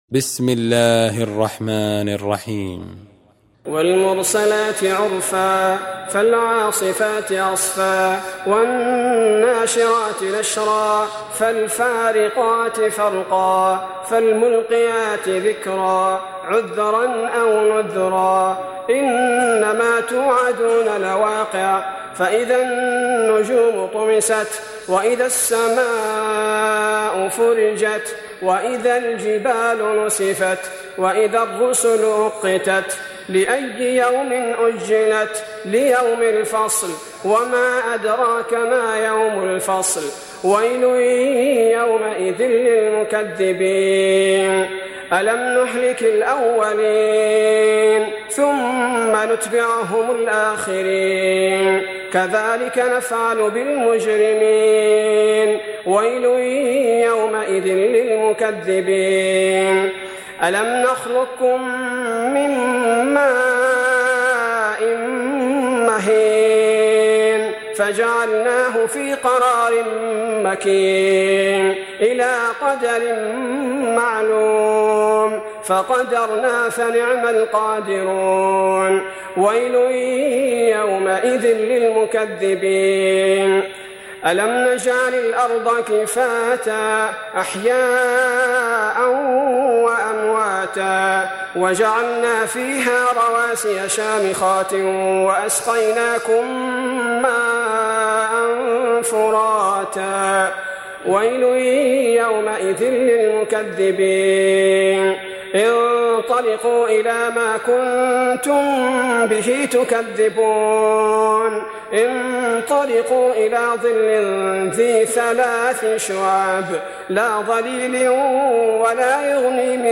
Surah Sequence تتابع السورة Download Surah حمّل السورة Reciting Murattalah Audio for 77. Surah Al-Mursal�t سورة المرسلات N.B *Surah Includes Al-Basmalah Reciters Sequents تتابع التلاوات Reciters Repeats تكرار التلاوات